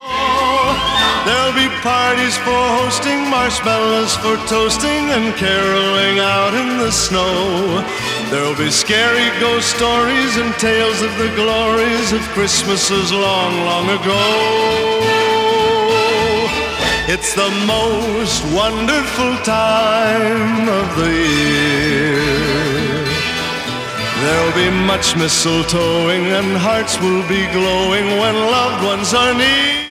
• Holiday
popular Christmas song written in triple time